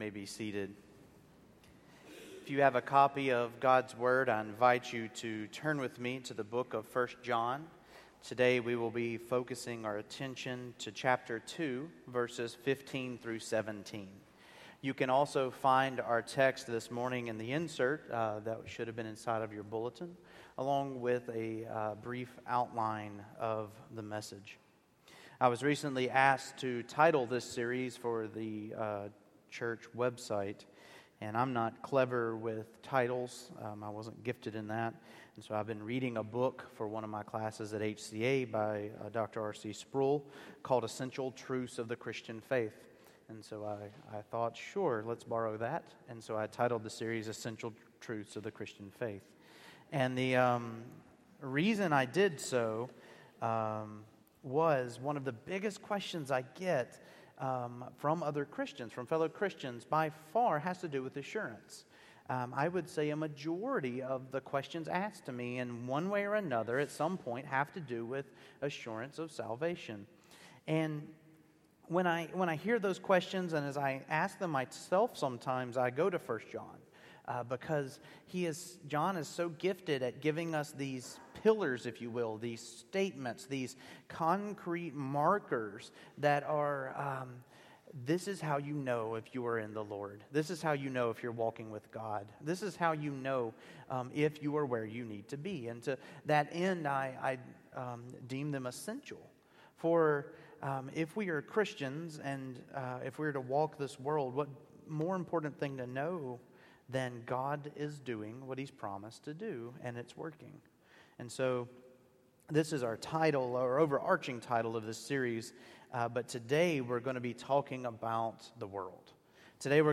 1 John 2:15-17 Service Type: Morning Worship I. Either love the world or love God